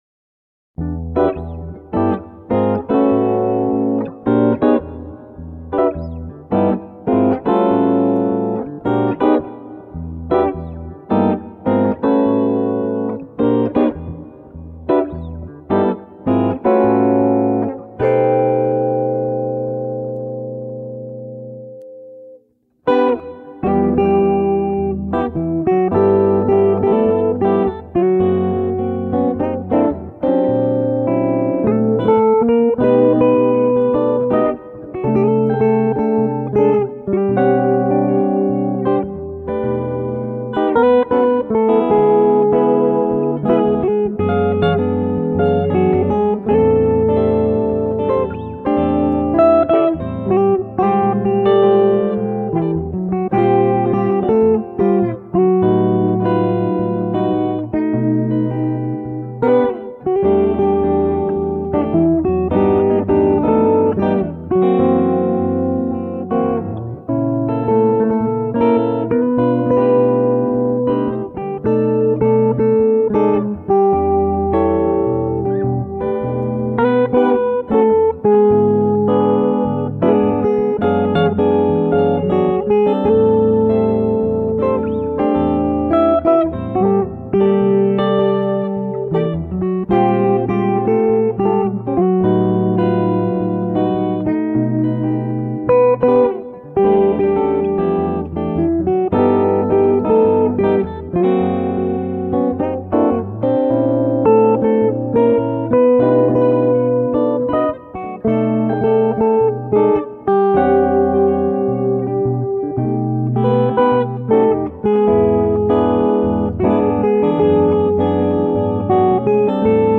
2207   05:05:00   Faixa:     Jazz
Guitarra